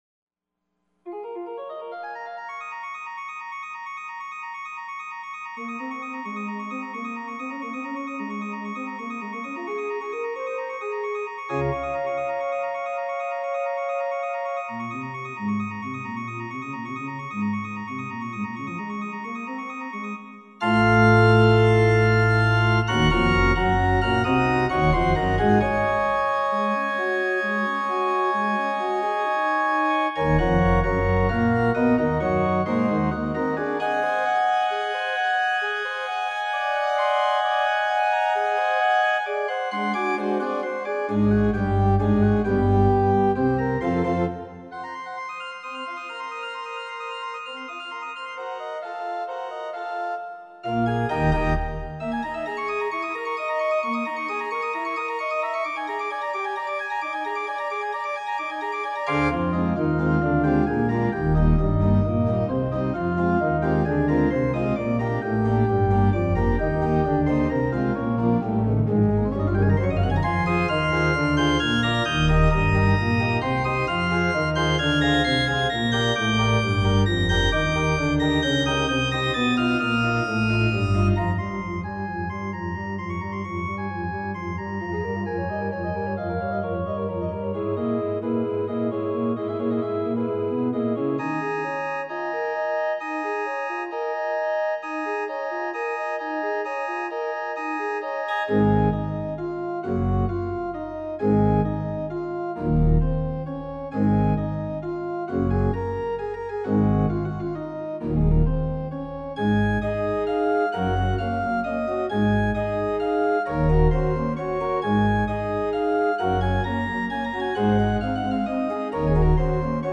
Here you can listen to screeching renditions of songs you will barely recognize!
Jupiter from The Planets by Gustav Holst - Another Lemare-ish organ transcription I did during the same period, this also is performed by my laptop computer playing the 100 stop organ from Ubberwerk.